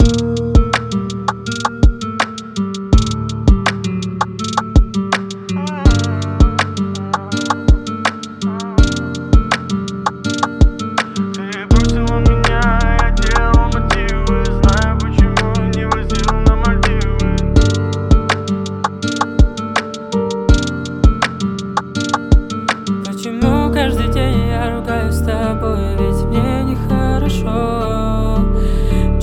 • Качество: 320, Stereo
мужской голос
лирика
красивая мелодия
басы
романтичные